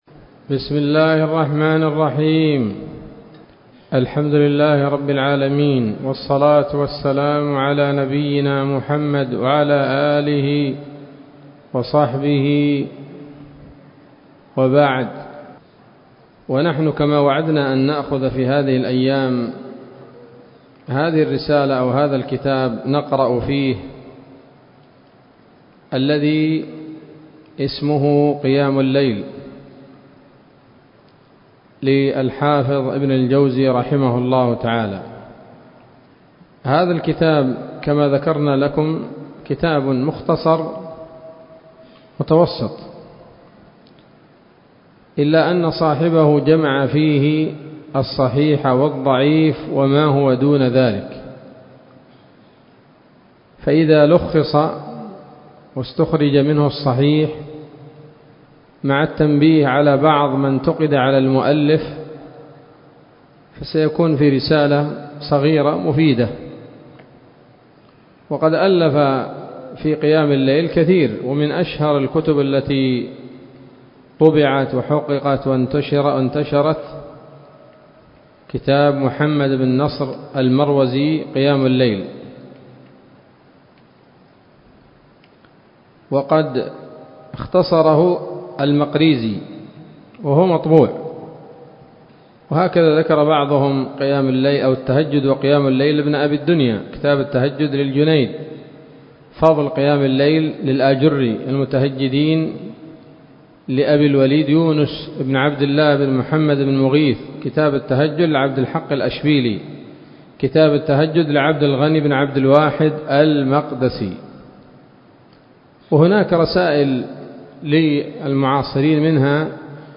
الدرس الأول من كتاب "قيام الليل" لابن الجوزي رحمه الله تعالى